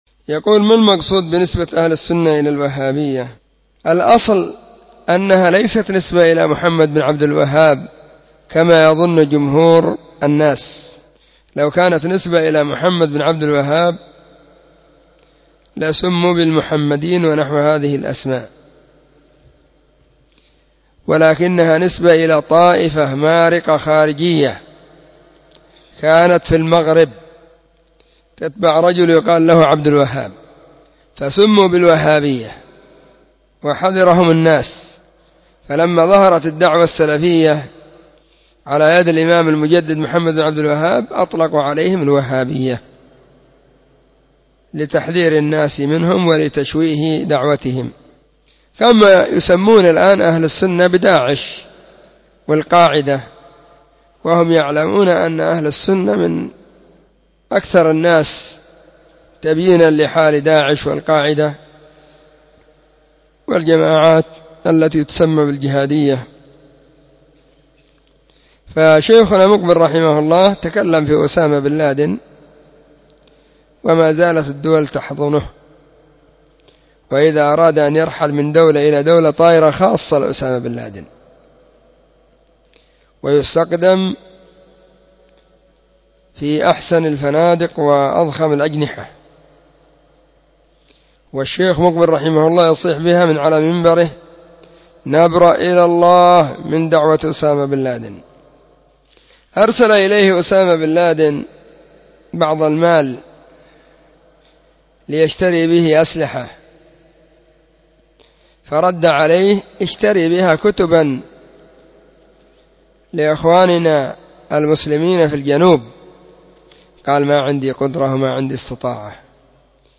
🔹 سلسلة الفتاوى الصوتية 🔸الأحد 7 /محرم/ 1443 هجرية. ⭕ أسئلة ⭕ 4